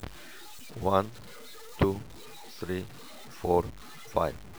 sony-vegas-distort.wav